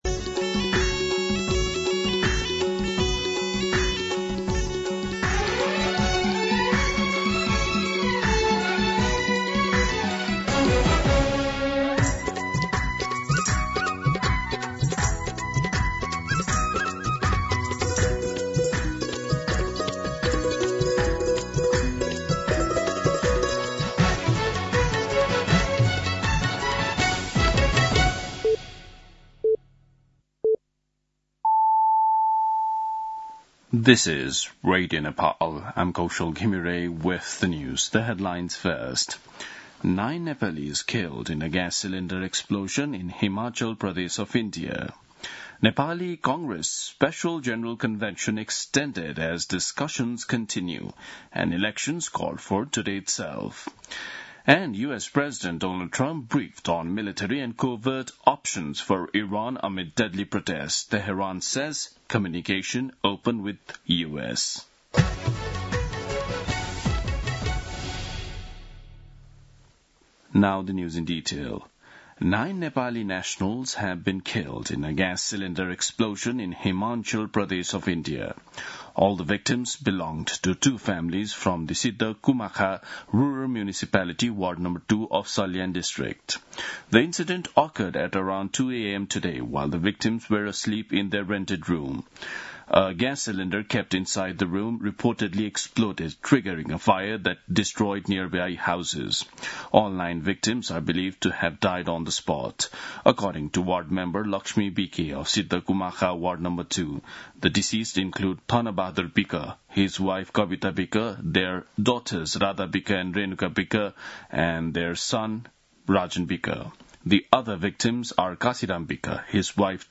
दिउँसो २ बजेको अङ्ग्रेजी समाचार : २९ पुष , २०८२
2pm-News-09-29.mp3